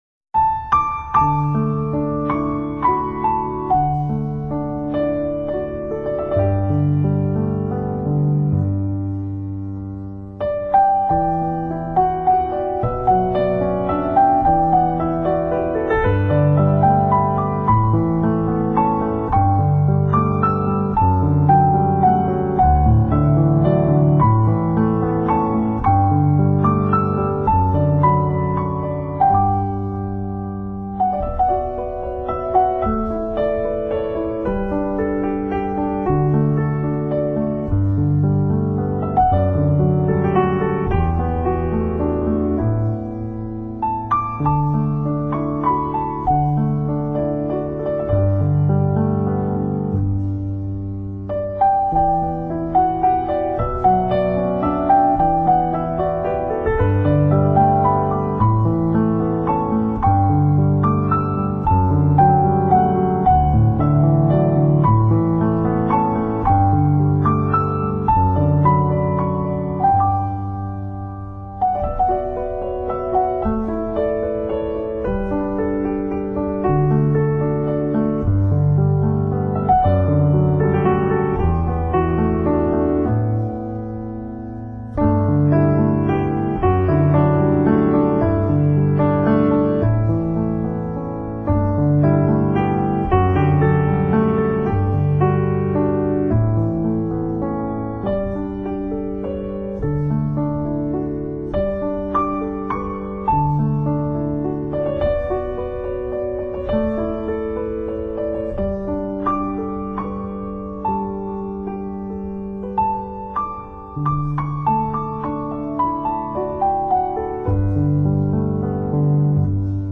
主奏乐器：钢琴
★缤纷、脱俗、浓郁的幸福感
★无法模仿的柔软与韧性